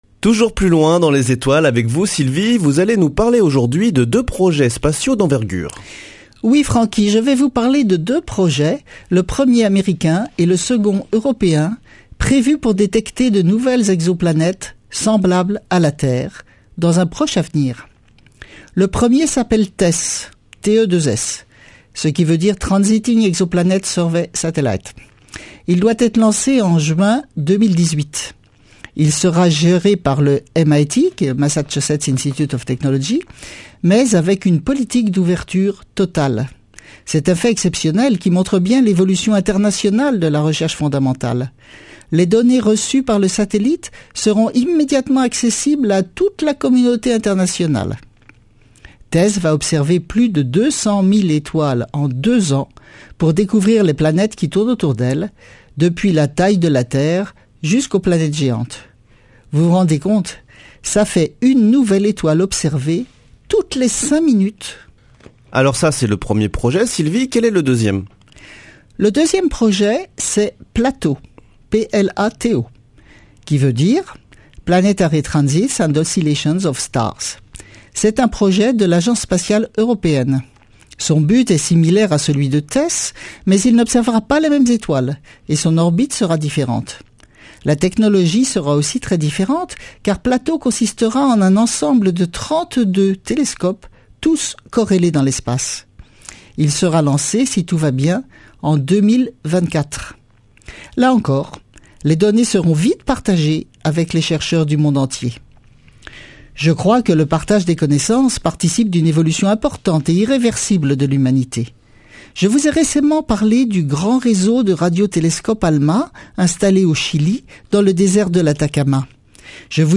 Chronique Astrophysique
Speech